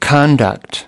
conduct-noun.mp3